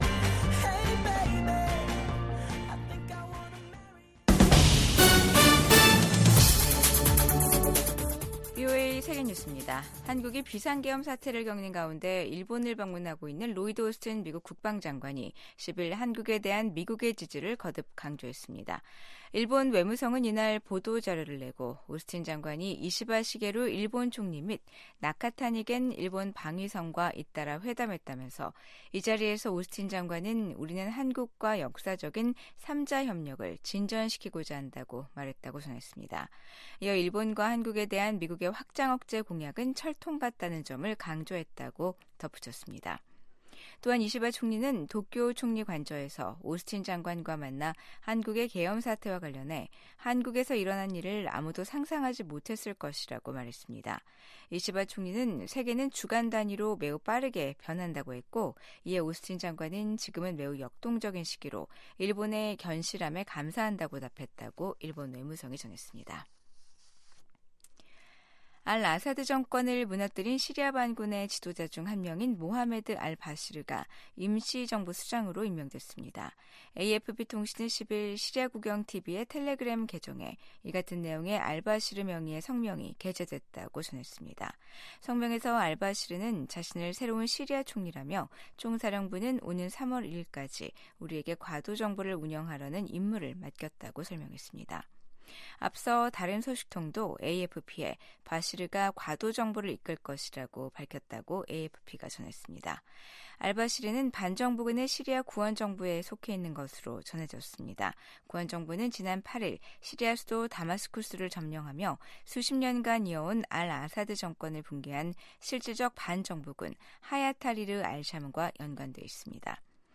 VOA 한국어 아침 뉴스 프로그램 '워싱턴 뉴스 광장'입니다. 비상계엄 사태를 수사하는 한국 검찰은 이 사태를 주도한 혐의를 받고 있는 김용현 전 국방부 장관에 대해 구속영장을 청구했습니다. 미국 국무부는 한국의 정치적 혼란 상황이 법치에 따라 해결돼야 한다는 원칙을 재확인했습니다.